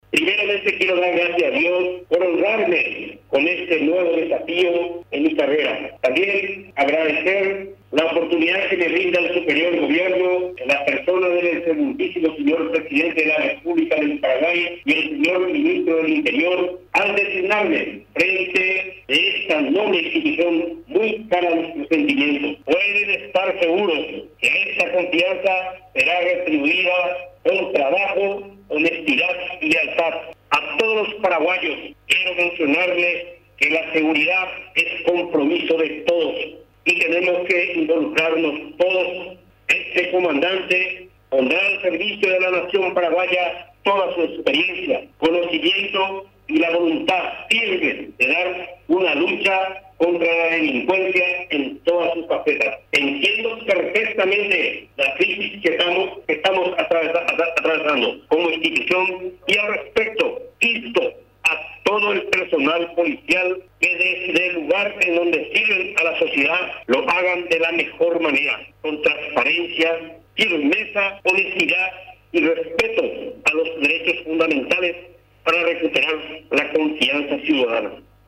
En el acto de juramento, agradeció la confianza depositada en su persona para resguardar la seguridad ciudadana.